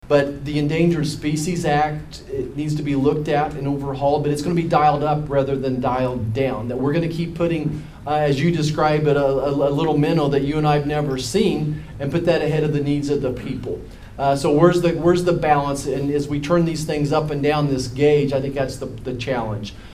Senator Roger Marshall spoke to members of the community during his town hall over the weekend.